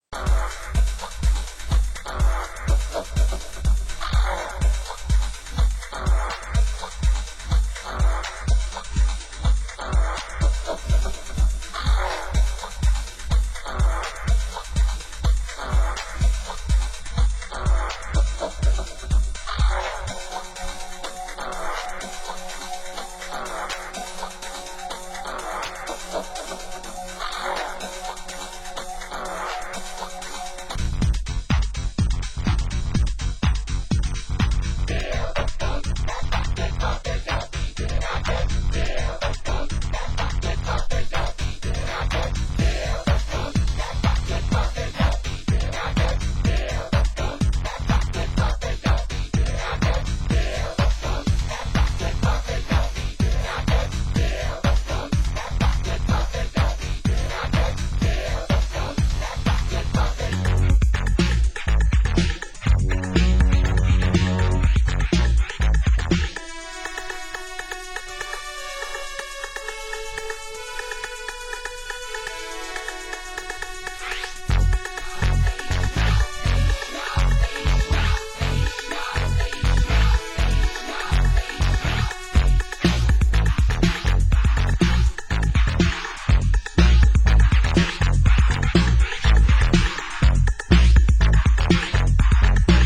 Genre: Old Skool Electro